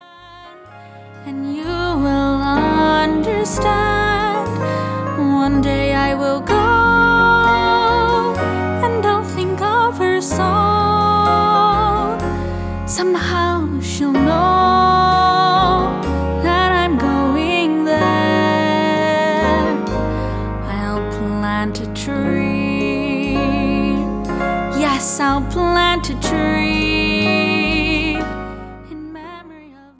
A Musical Download Album for $11.99
cast recording